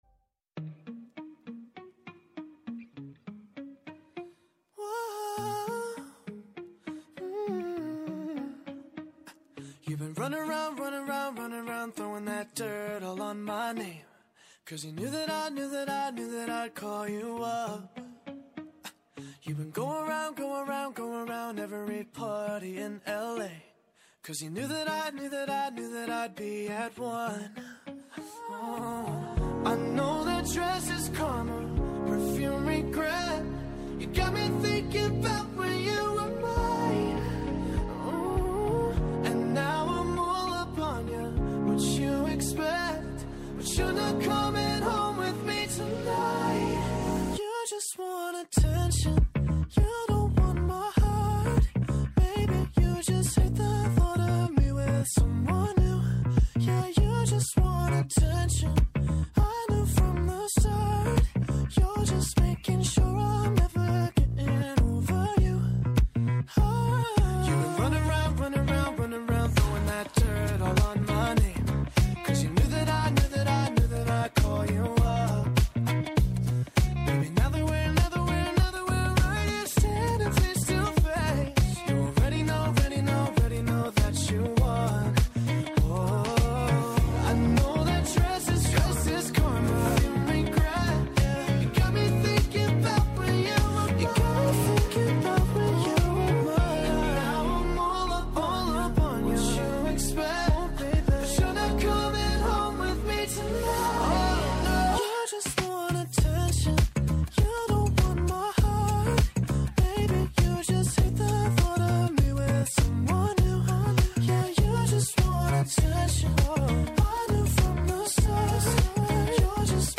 Καλεσμένος ο αρχιτέκτονας